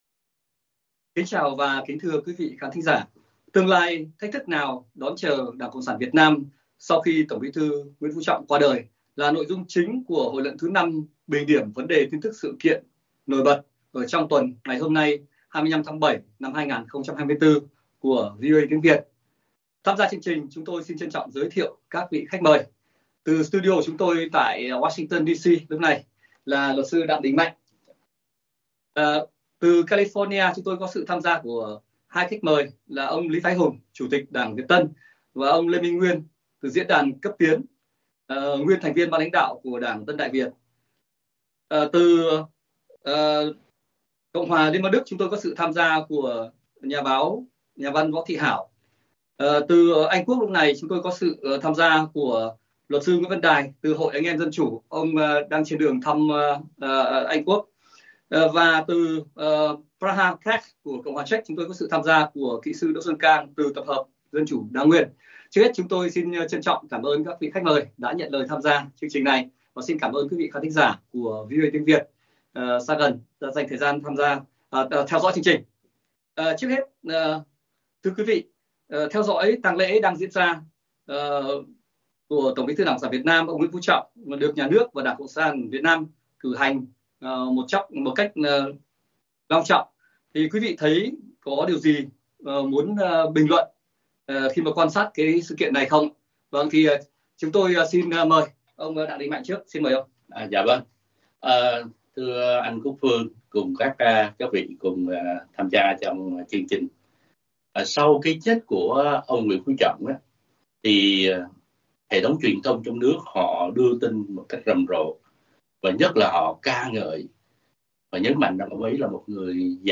Các khách mời của VOA Tiếng Việt từ Hoa Kỳ và hải ngoại thảo luận biến cố thời sự, chính trị liên quan chính trường Việt Nam đang là trọng tâm chú ý, quan tâm của công luận và giới quan sát, phân tích tuần này, sau khi TBT ĐCSVN Nguyễn Phú Trọng qua đời tại Hà Nội ở tuổi 80.